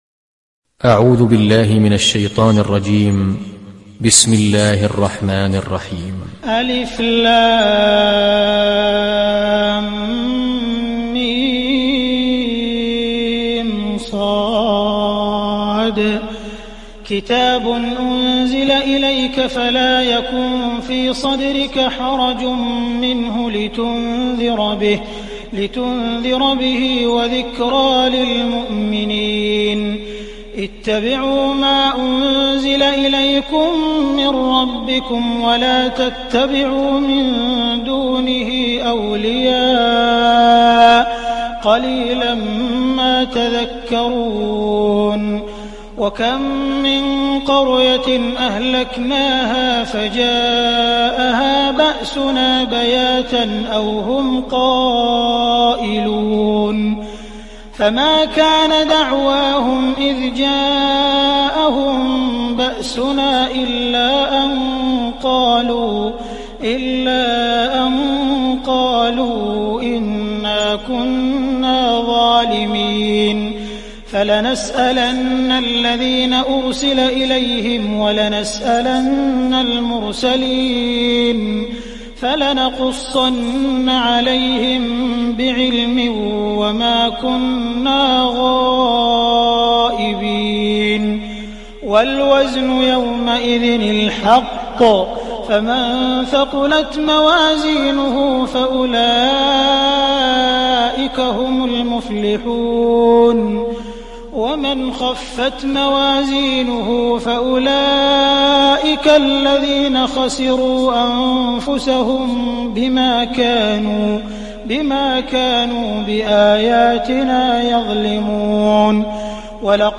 تحميل سورة الأعراف mp3 بصوت عبد الرحمن السديس برواية حفص عن عاصم, تحميل استماع القرآن الكريم على الجوال mp3 كاملا بروابط مباشرة وسريعة